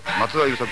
All sounds in WAV format are spoken by Yusaku himself (Also Andy Garcia and Michael Douglas).
You can find how to pronounce his name. This one is from Tantei-Monogatari (TV) preview, so he reads it very fast for limited time. It sounds pretty flat accent than usual.